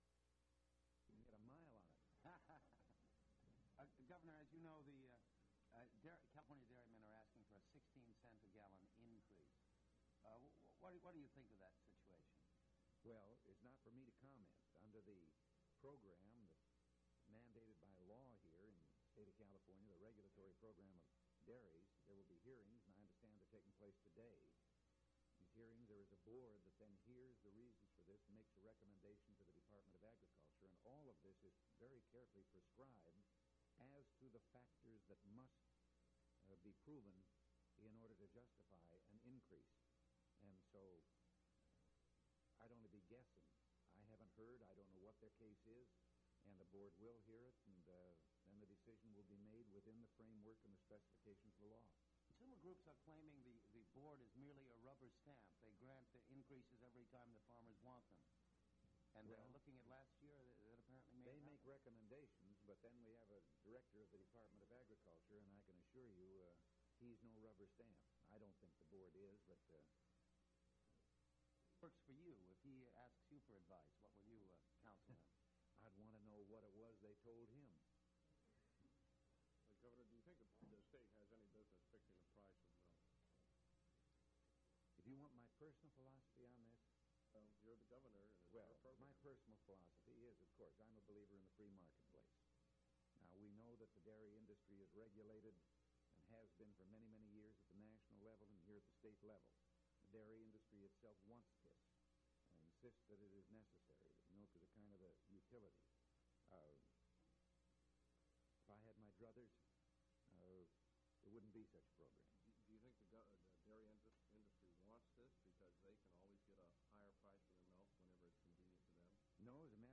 Governor Ronald Reagan News Conference
Audio Cassette Format.